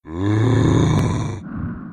/Bufer1/zombies/sounds/
mgroan15.mp3